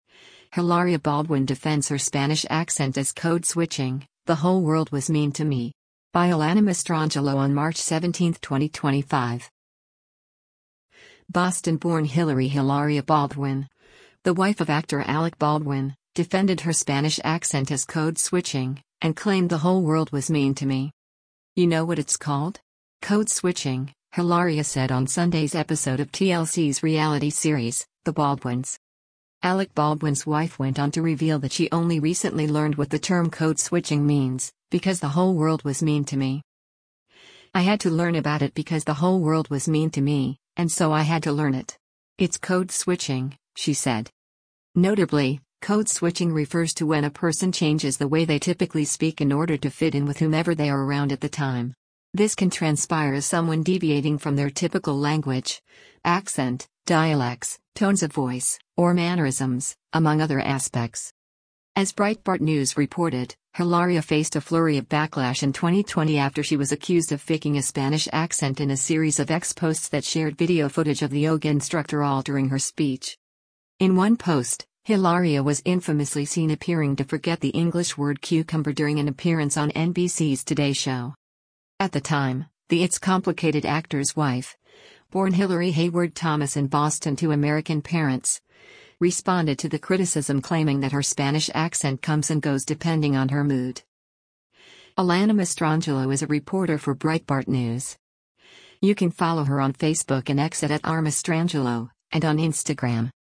Hilaria Baldwin Defends Her Spanish Accent as 'Code-Switching': The Whole World was Mean to Me'
“You know what it’s called? Code-switching,” Hilaria said on Sunday’s episode of TLC’s reality series, The Baldwins.
At the time, the It’s Complicated actor’s wife — born Hillary Hayward-Thomas in Boston to American parents — responded to the criticism claiming that her Spanish accent comes and goes depending on her mood.